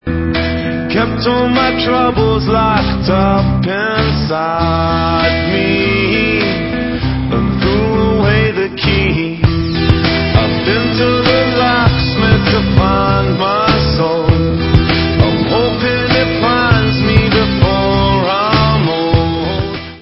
sledovat novinky v kategorii Dance